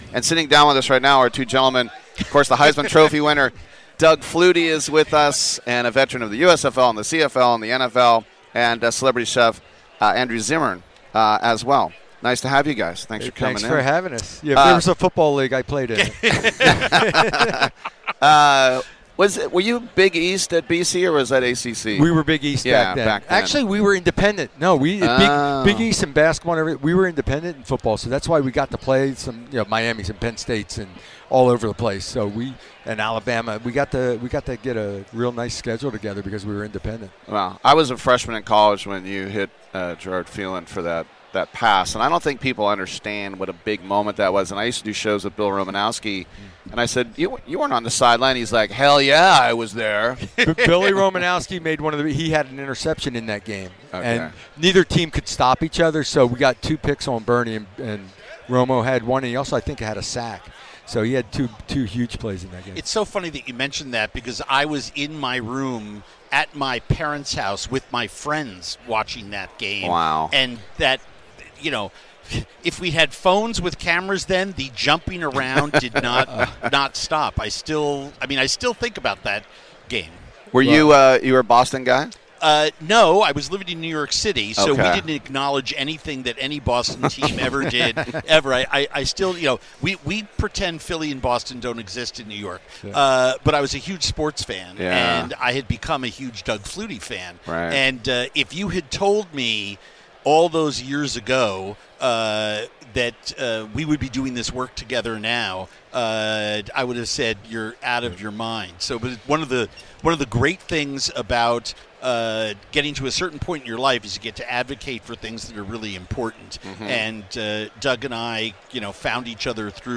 From Super Bowl LX Media Row: Heisman Winner Doug Flutie and Award-Winning Chef Andrew Zimmern